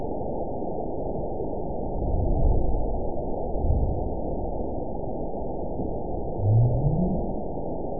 event 912354 date 03/25/22 time 06:22:12 GMT (3 years, 1 month ago) score 9.67 location TSS-AB04 detected by nrw target species NRW annotations +NRW Spectrogram: Frequency (kHz) vs. Time (s) audio not available .wav